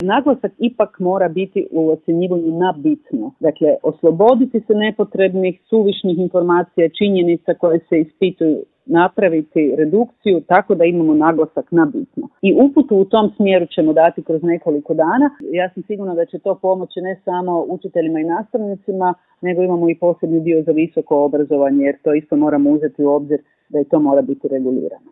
Kako će se učenici ocjenjivati, hoće li se odgoditi matura, što je s upisima na fakultete...mnoštvo je pitanja na koje brojni traže odgovore. Mi smo odgovore potražili od ministrice znanosti i obrazovanja Blaženke Divjak u Intervjuu tjedna Media servisa.